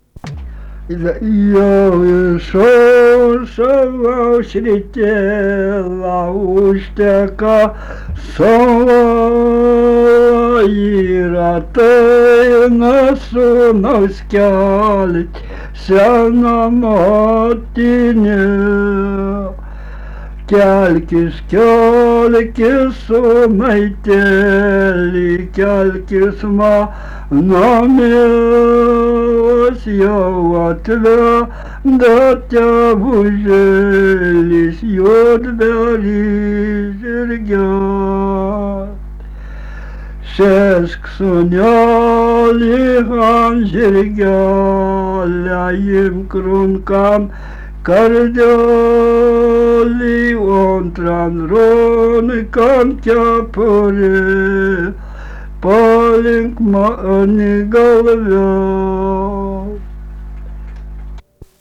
daina
Krapiškis
vokalinis